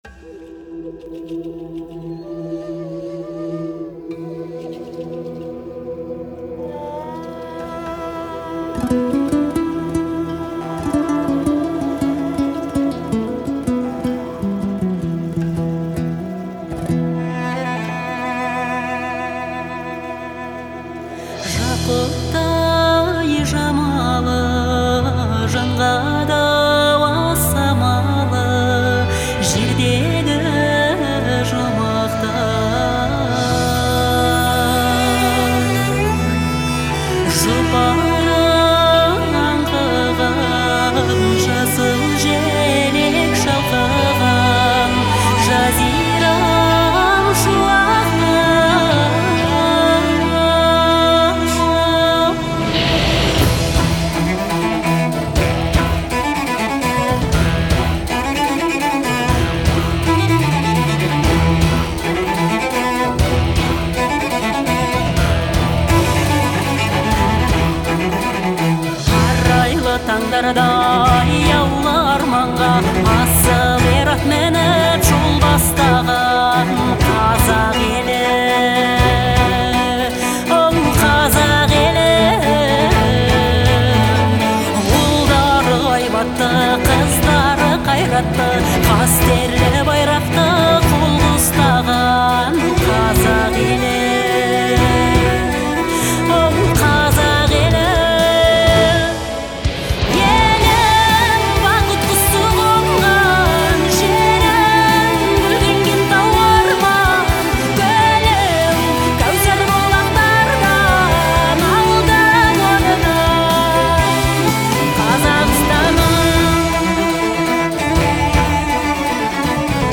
представляет собой яркий пример казахской поп-музыки.
обладая мощным голосом